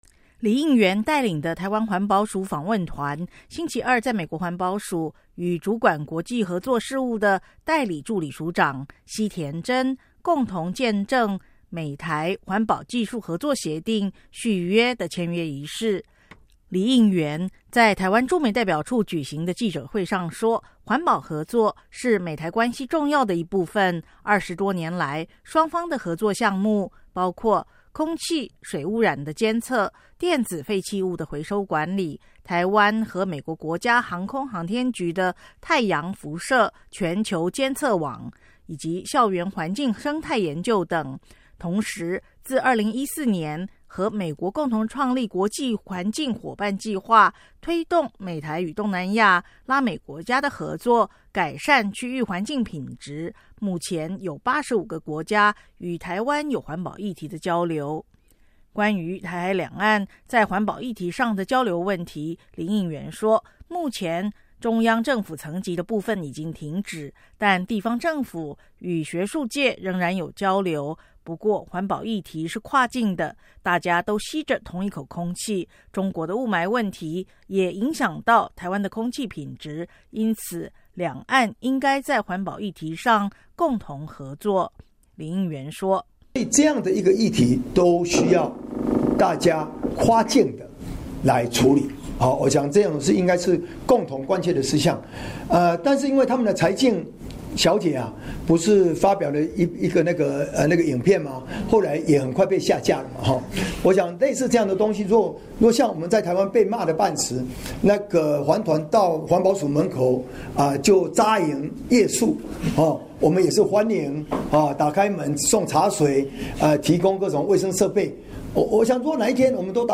李应元随后在双橡园举行的记者会上说，环保合作是美台关系重要的一部分，二十多年来双方的合作项目包括空气、水污染的监测、电子废弃物(E-waste)的回收管理、台湾与美国国家航空航天局(NASA)的太阳辐射全球监测网(AERONET)、校园环境生态研究等，同时自2014年和美国共同创立“国际环境伙伴”计划，推动美台与东南亚、拉美国家的合作，改善区域环境品质，目前有85个国家与台湾有环保议题的交流。